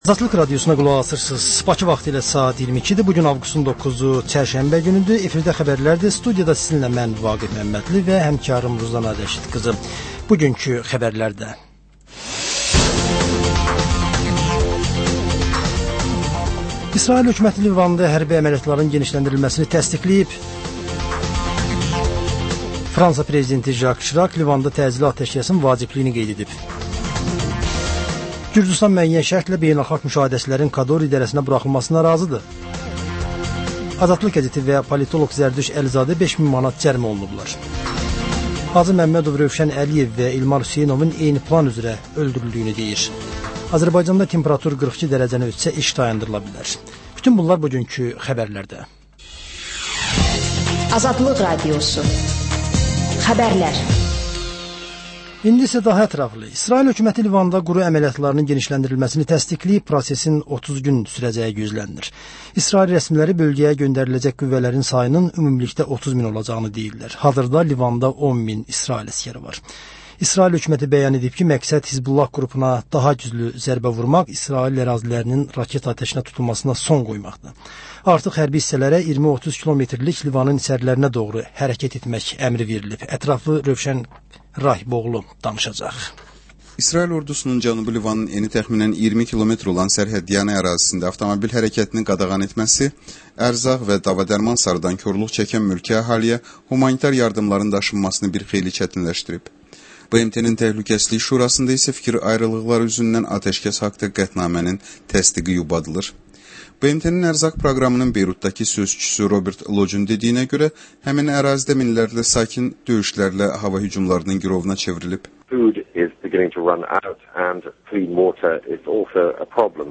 Xəbər, reportaj, müsahibə. Sonra: 14-24: Gənclərlə bağlı xüsusi veriliş.